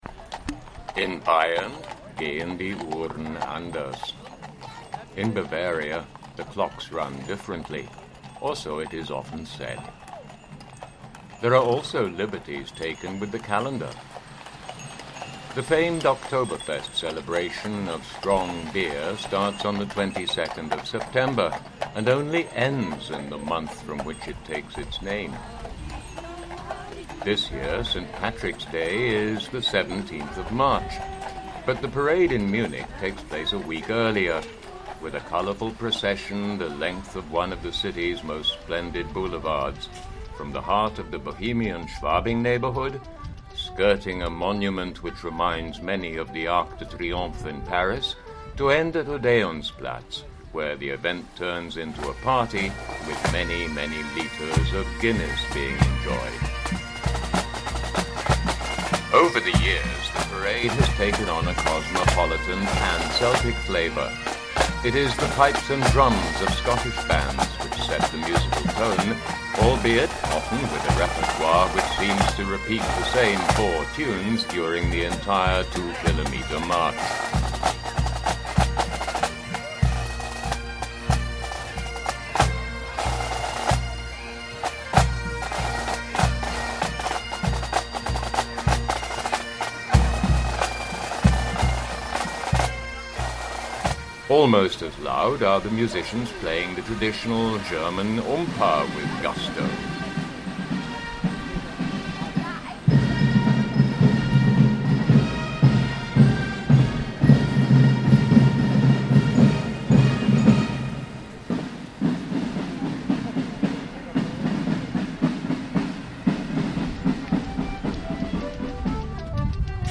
Munich St.Patrick's Day Parade 2012